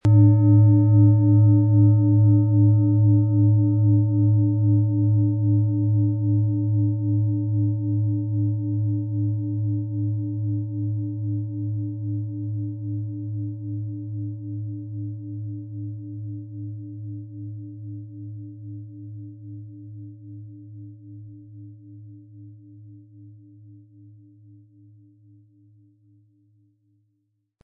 Planetenschale® Verantwortlich sein können & Konzentriert fühlen mit Saturn, Ø 24,4 cm, 1500-1600 Gramm inkl. Klöppel
Unter dem Artikel-Bild finden Sie den Original-Klang dieser Schale im Audio-Player - Jetzt reinhören.
Viel Freude haben Sie mit einer Saturn, wenn Sie sie sanft mit dem kostenlosen Klöppel anspielen.
PlanetentonSaturn & Merkur (Höchster Ton)
MaterialBronze